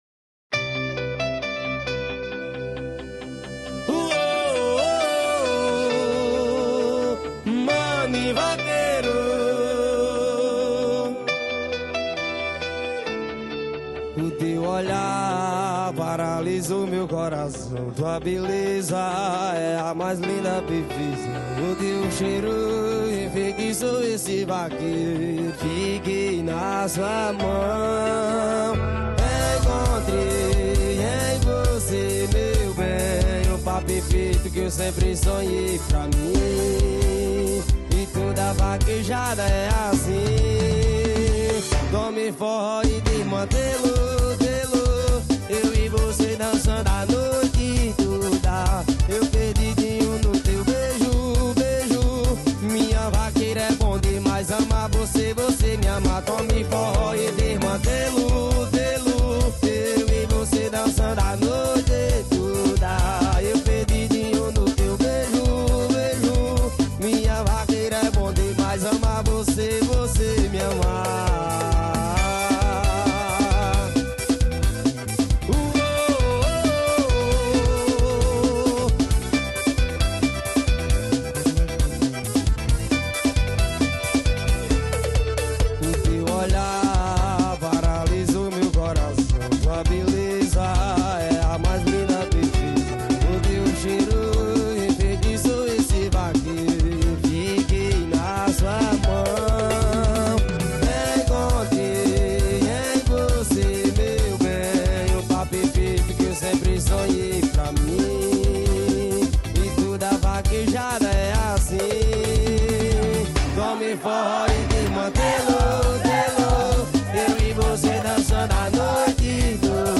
2025-01-23 11:34:53 Gênero: Forró Views